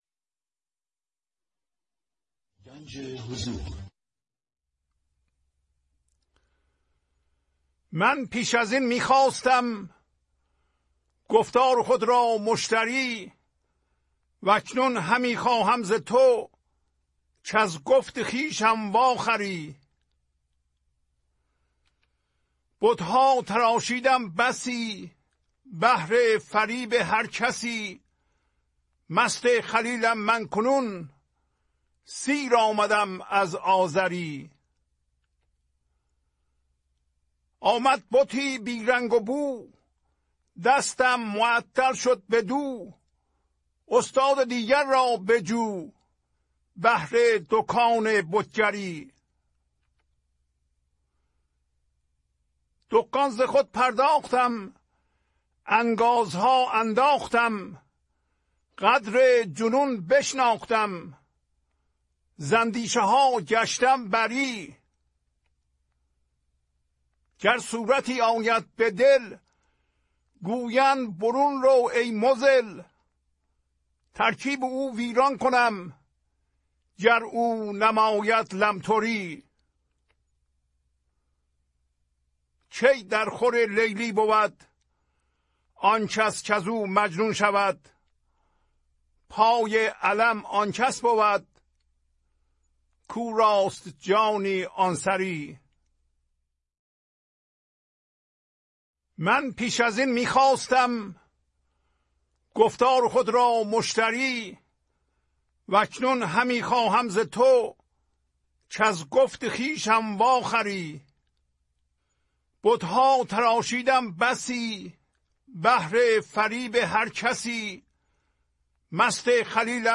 1014-Poems-Voice.mp3